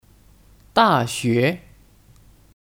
大学 (Dàxué 大学)